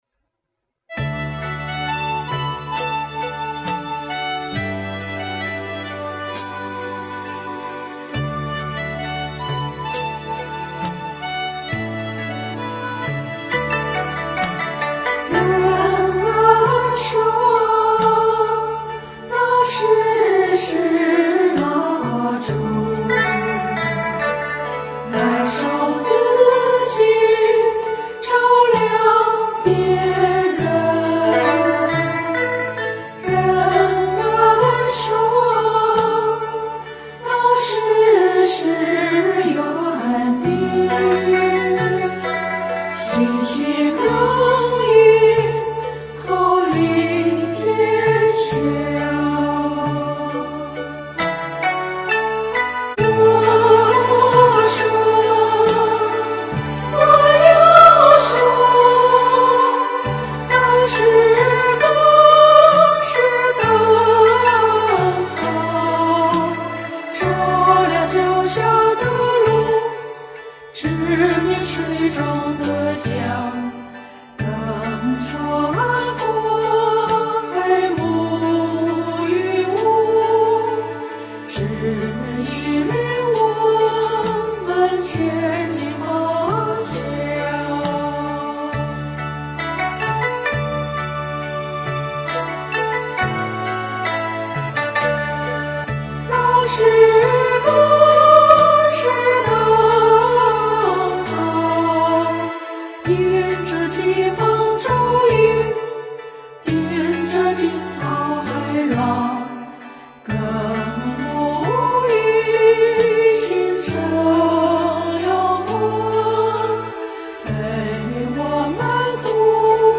电钢琴乐